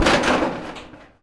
metal_sheet_impacts_10.wav